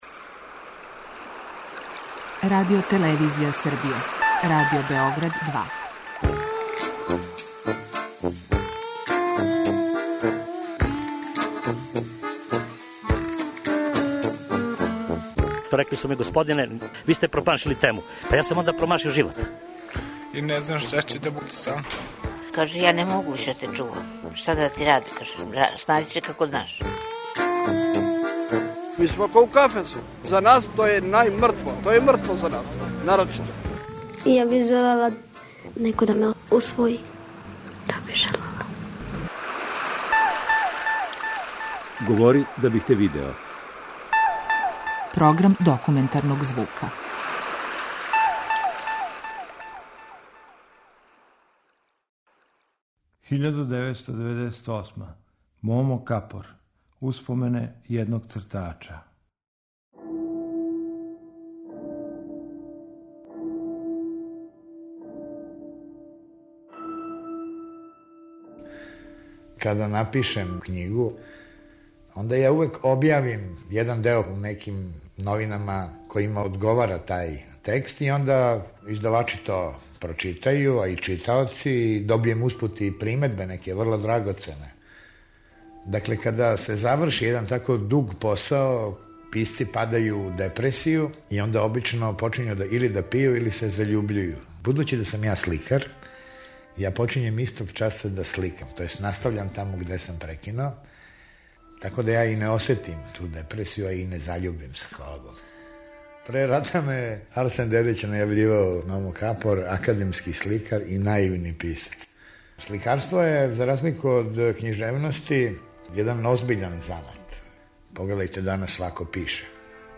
Документарни програм
Поводом 10 година од смрти Моме Капора емитујемо емисију Успомене једног цртача, у којој је овај познати уметник говорио о себи и свом стваралаштву.